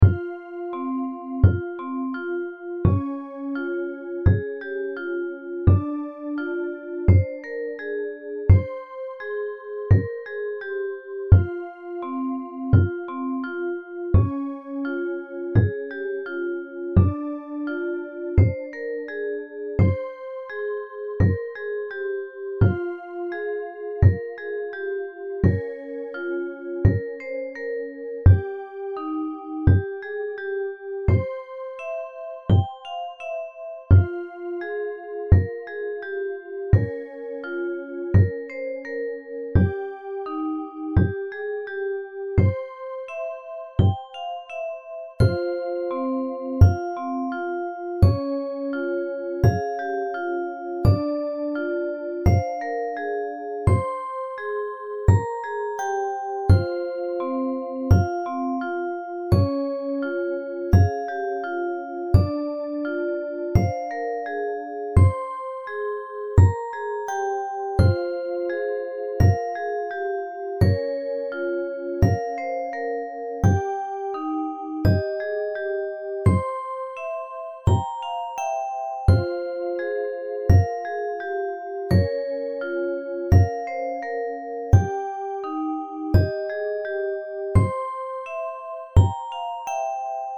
In your first one, that wind synth has some really high frequencies.
Then the quarter note drum hit comes in, I’m totally getting some Seven Nation Army vibes.
The first one was made to be looped and it sounds much better when it is.